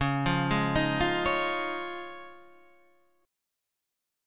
C# Major Add 9th